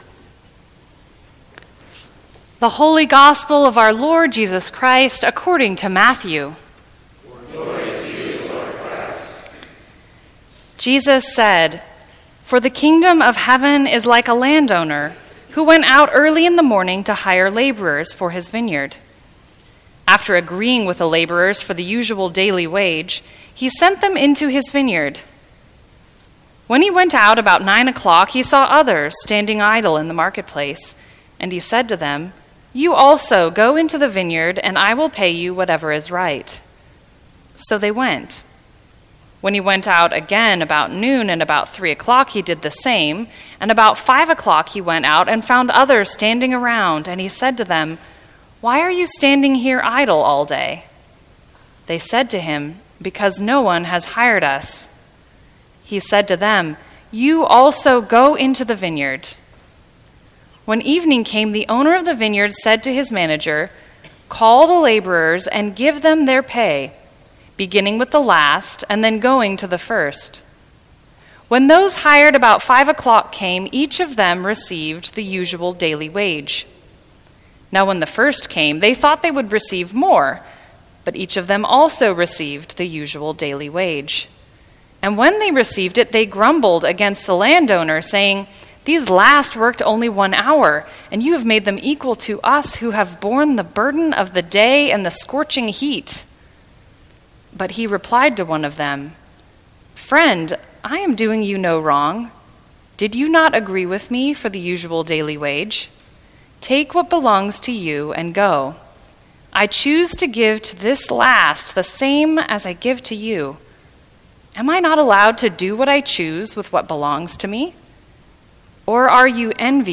Sixteenth Sunday after Pentecost, 10 AM
Sermons from St. Cross Episcopal Church To See One Another Sep 29 2017 | 00:15:38 1x 00:00 / 00:15:38 Subscribe Share Apple Podcasts Spotify Overcast RSS Feed Share Link Embed